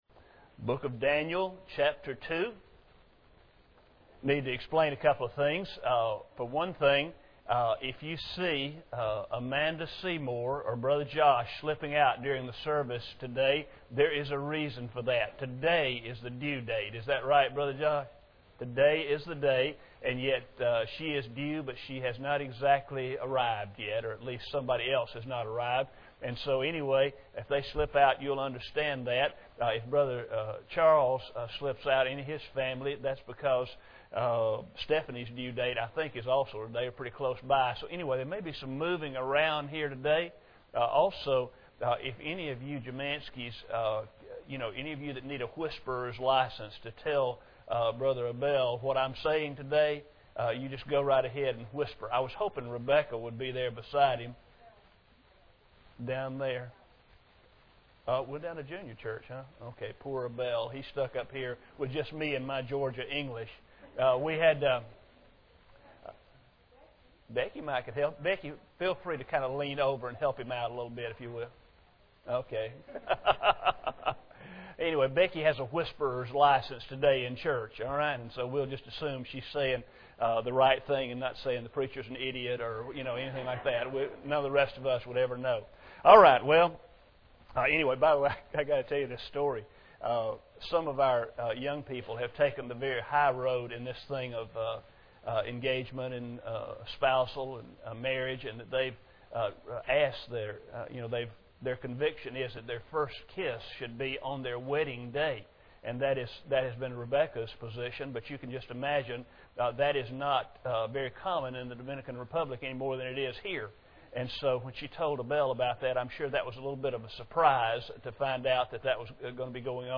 Daniel 2:1-49 Service Type: Sunday Morning Bible Text